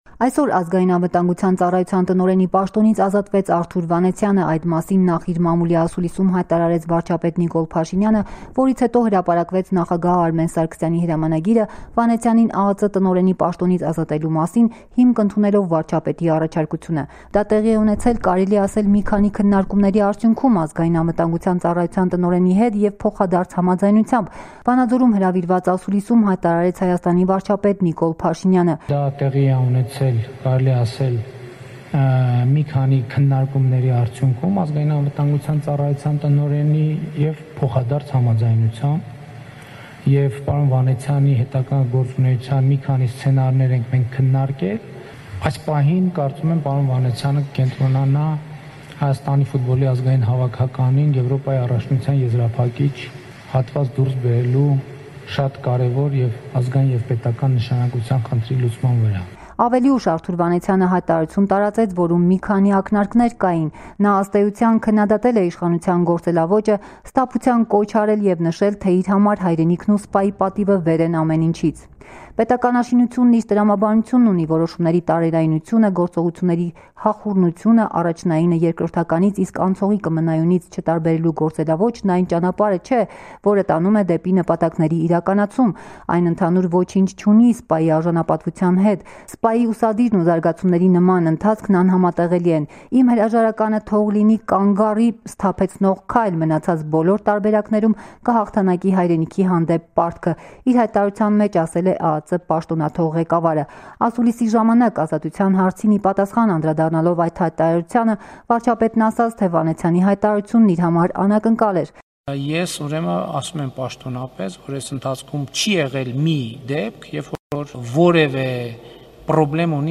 Armenia -- PM Pashinian speaks at the Ceremony Devoted to Police Day. 16April, 2019